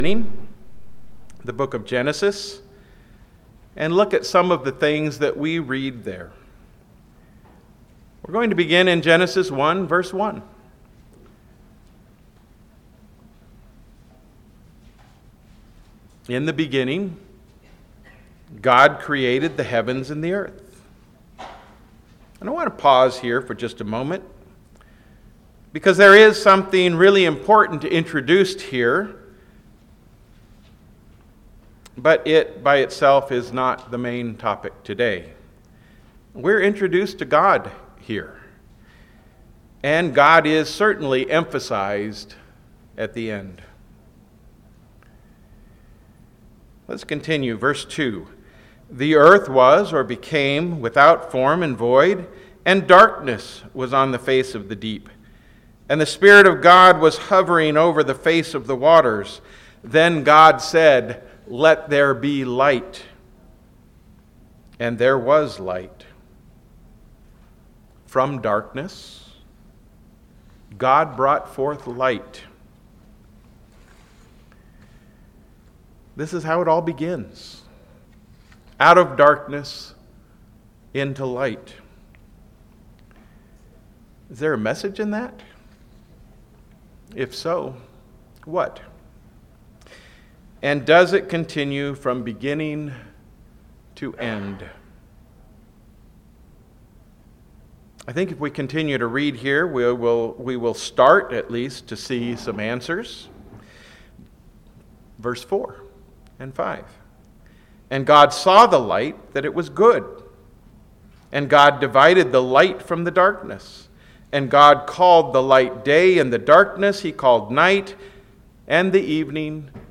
This sermon was given at the Anchorage, Alaska 2021 Feast site.